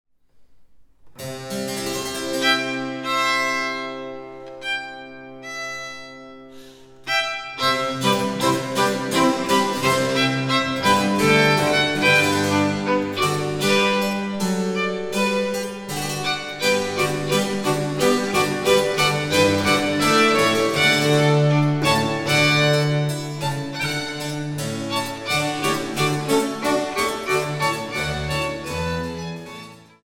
Violine
Violone
Orgel & Cembalo
Sonata 81 in A-Dur * + (Anonym): Skordatur a - e‘ - a‘ - e‘‘